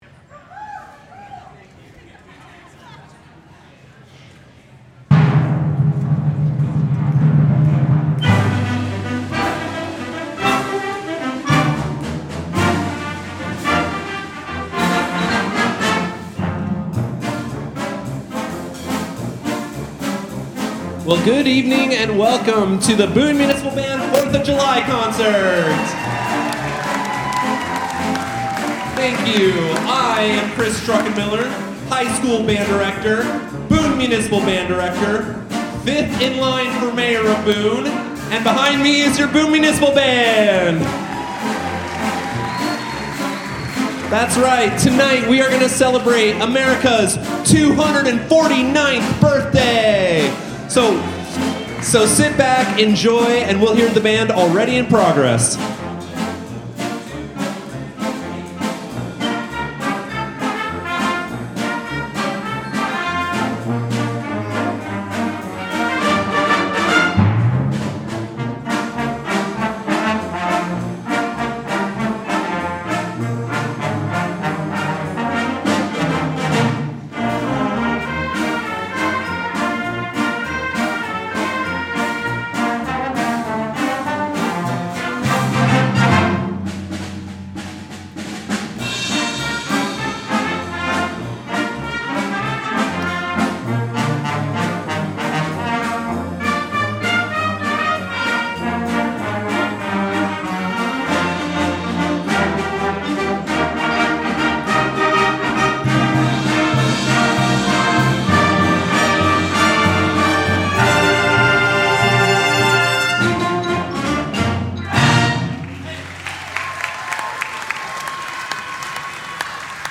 The Boone Municipal Band performed their annual Patriotic Concert on July 4th at Herman Park Pavilion.